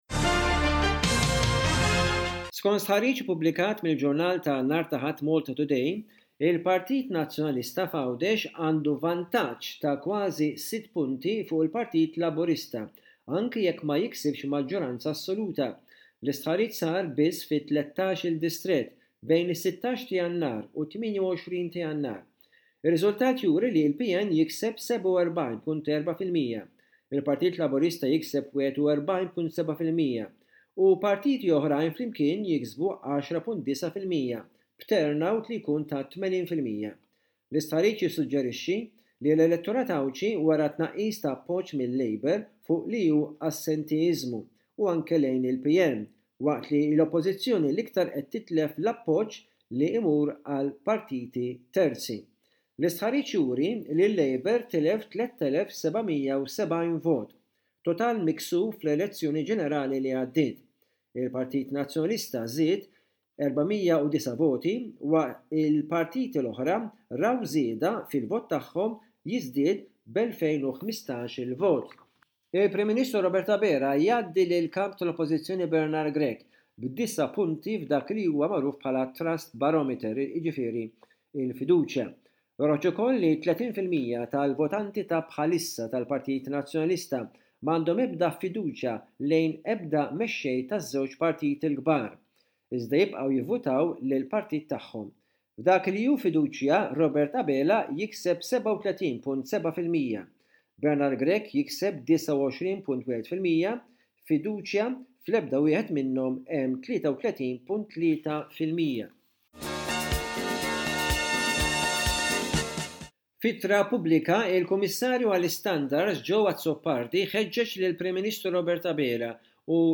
Aħbarijiet minn Malta: 12.02.25